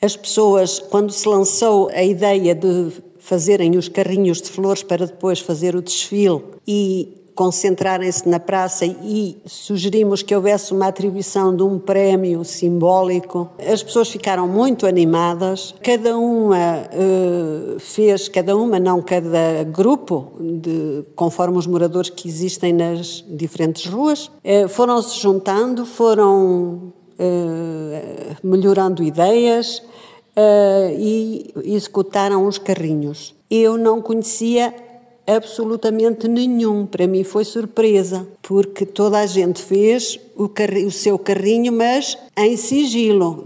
O programa contou com diversas iniciativas, uma delas foi o desfile de carrinhos de flores pelas ruas com a atribuição de prémios, que envolveu a participação da comunidade, como acrescentou a presidente: